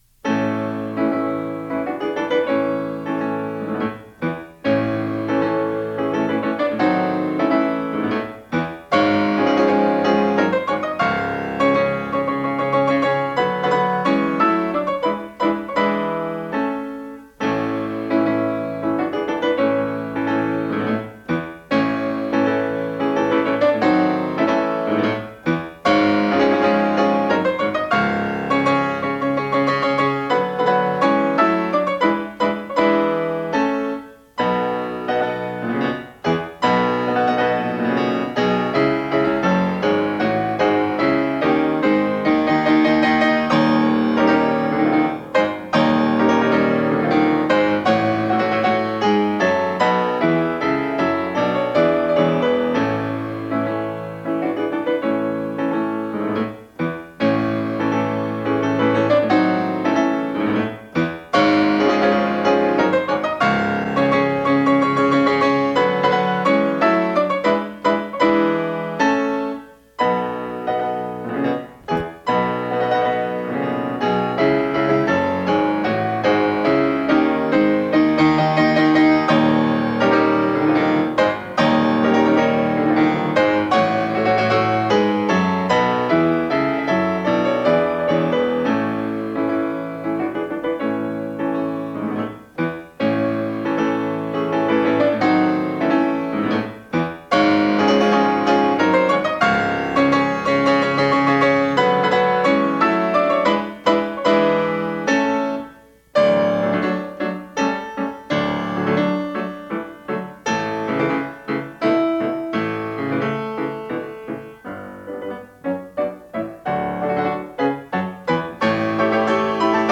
Arthur Rubinstein (piano) - Polonaise, No.3, Op.40, No.1 Military in A major (Chopin) (1950)